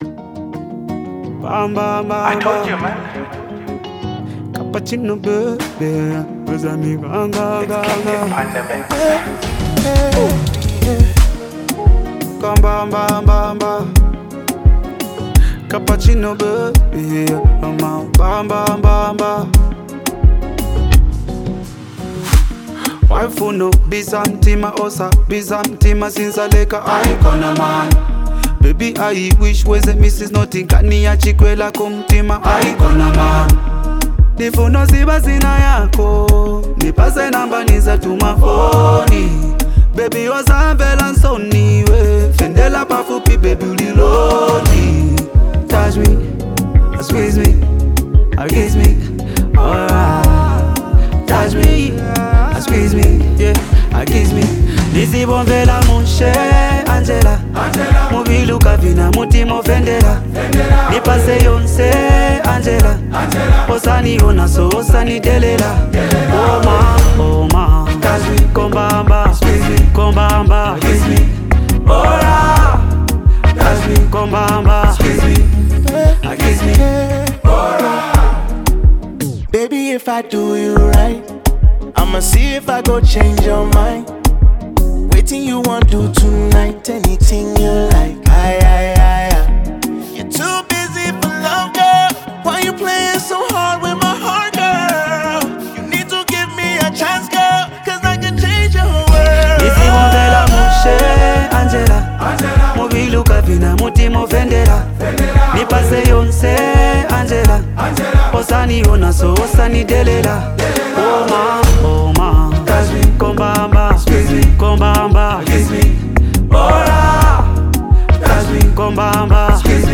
actor and R&B singer